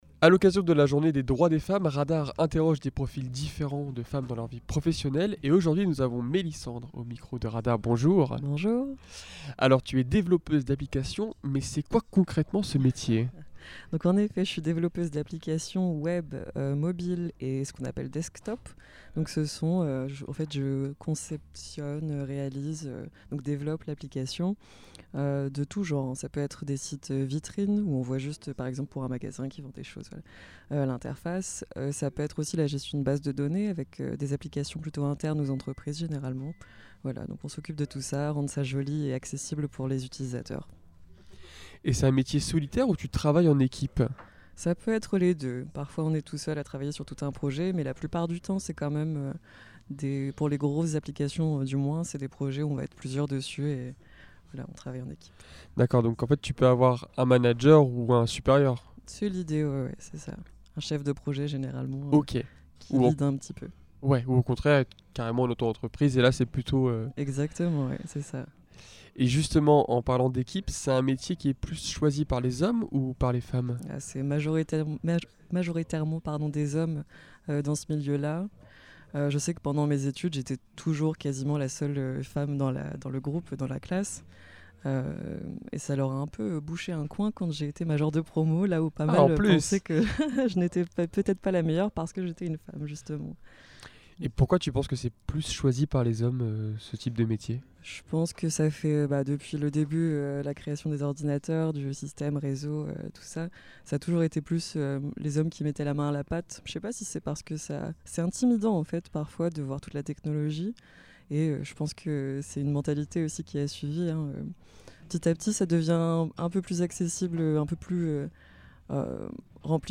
Nous les avons interrogées sur leur vécu et leur vision de la société.